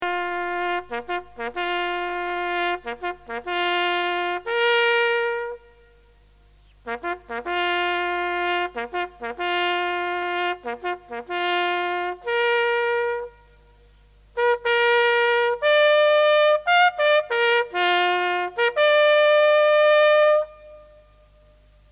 Reveille
Reveille.mp3